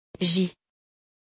• Prononciation : [ʒi]